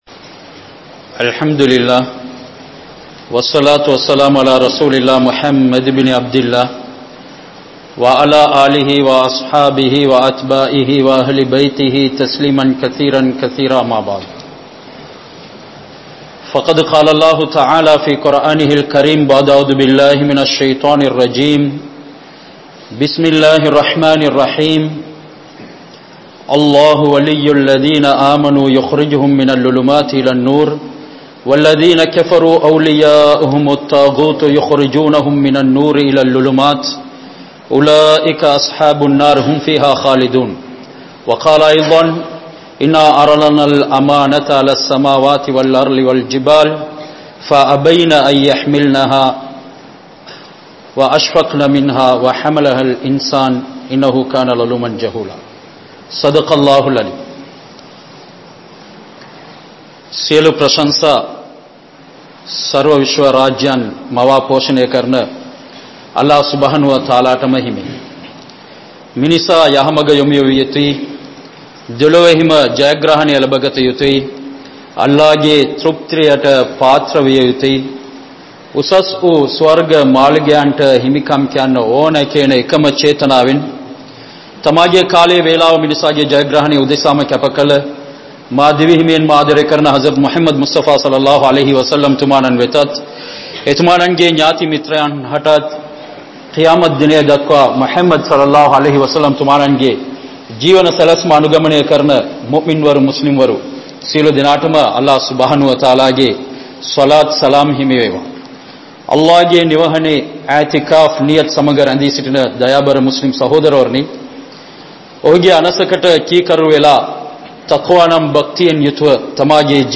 Irai Visuvaasien Oli (இறை விசுவாசியின் ஒளி) | Audio Bayans | All Ceylon Muslim Youth Community | Addalaichenai